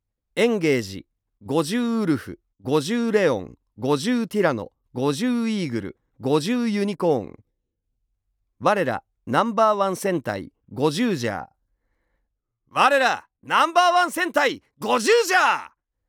さらに人気声優の関智一氏朗詠による読み上げCDが付属！